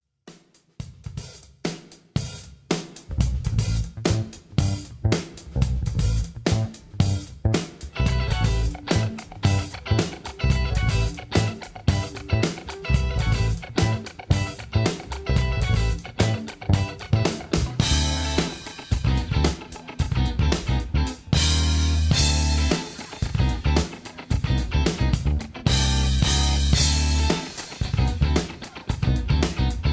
Tags: backing tracks, karaoke, backing tracks s, rock